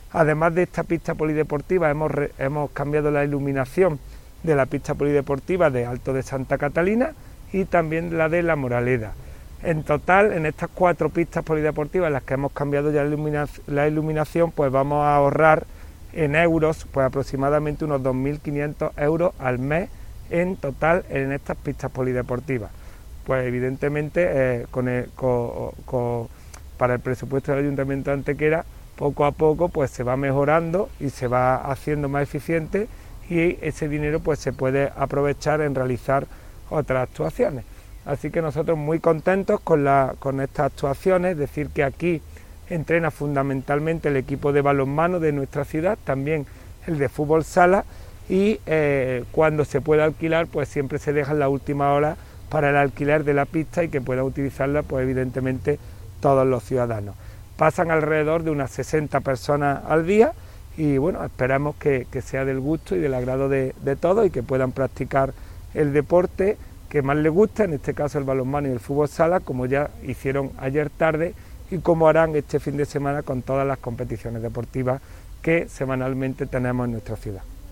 El alcalde de Antequera, Manolo Barón, y el teniente de alcalde delegado de Deportes, Juan Rosas, han informado en rueda de prensa sobre la conclusión de los trabajos desarrollados para la mejora integral de la pista polideportiva de Parquesol, anexa al pabellón Fernando Argüelles y a los campos de fútbol 7.
Cortes de voz